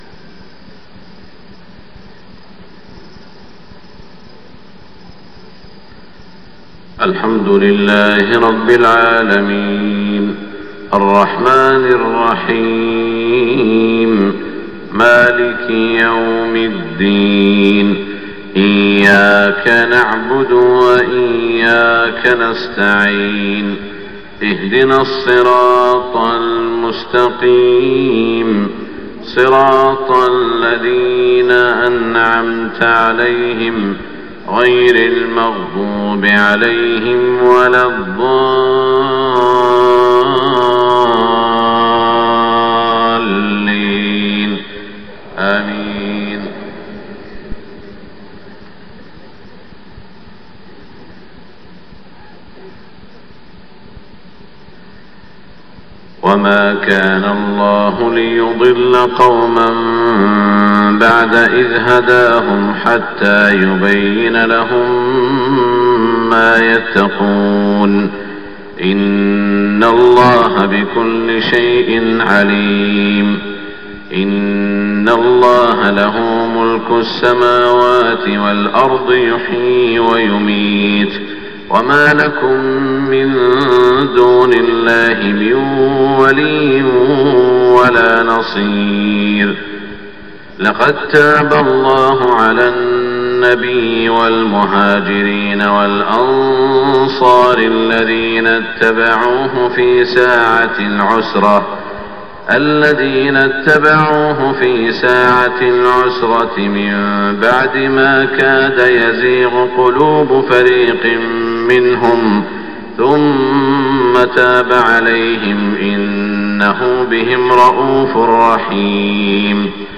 صلاة الفجر 9-1-1427 من سورة التوبة > 1427 🕋 > الفروض - تلاوات الحرمين